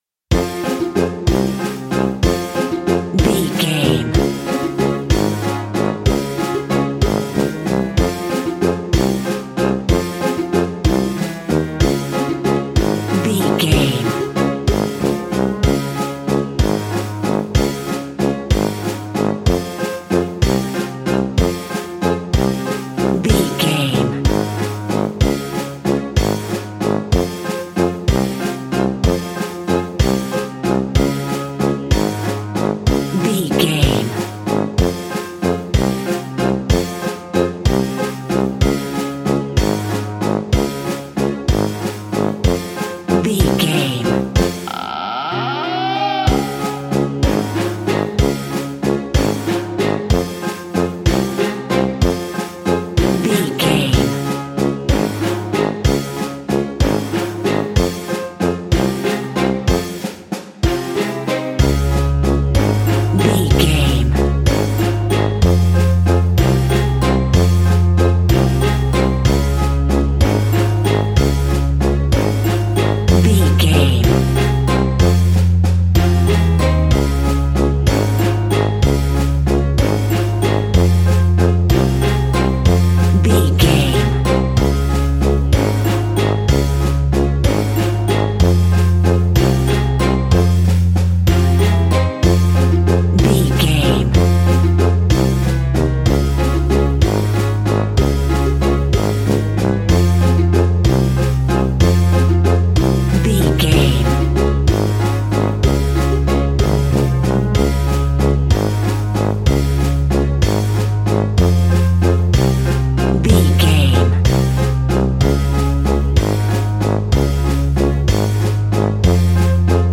Aeolian/Minor
ominous
dark
haunting
eerie
brass
electric organ
drums
piano
spooky
horror music